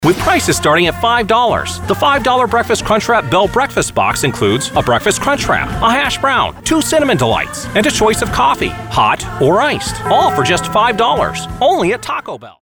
Male
English (North American), English (Neutral - Mid Trans Atlantic)
The rich depth of my voice instills confidence and trust.
Taco Bell Commercial Read